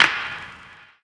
• Clap Sound G# Key 07.wav
Royality free clap sample - kick tuned to the G# note. Loudest frequency: 2219Hz
clap-sound-g-sharp-key-07-etb.wav